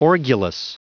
Prononciation audio / Fichier audio de ORGULOUS en anglais
Prononciation du mot : orgulous